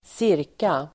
Ladda ner uttalet
ca förkortning, approx. Uttal: [²s'ir:ka] Synonymer: ungefär Definition: cirka, ungefär (approximately) Exempel: bordet var ca två meter långt (the table was about two metres long) ca. förkortning, ca , cirka